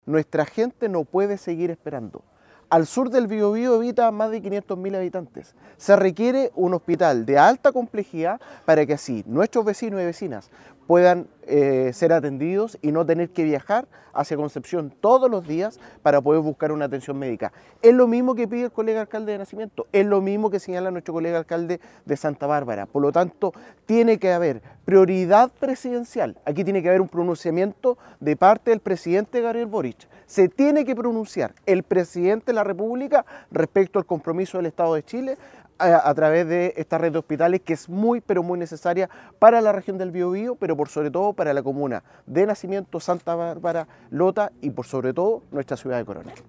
Boris Chamorro, alcalde de Coronel, llamó a transparentar la situación, exigiendo un pronunciamiento del Presidente Gabriel Boric “respecto del compromiso del estado de Chile con esta red de hospitales”.